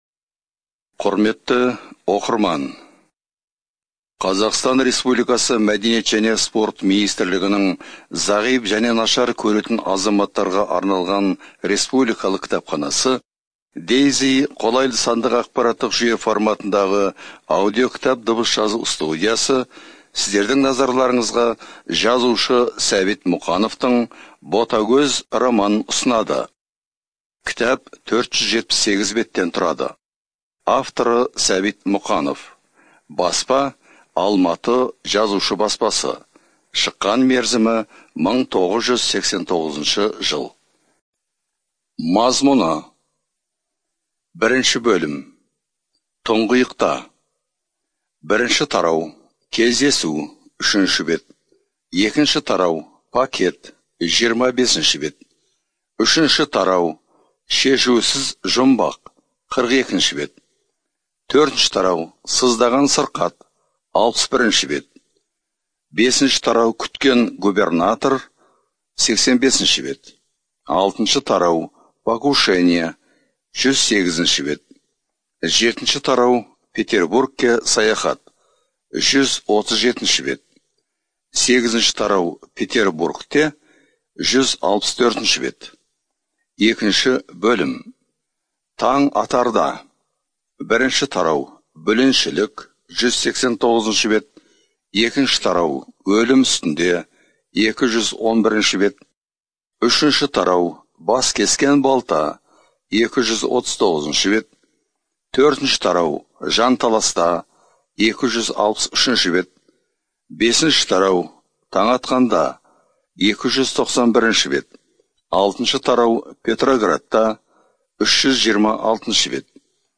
Студия звукозаписиКазахская республиканская библиотека для незрячих и слабовидящих граждан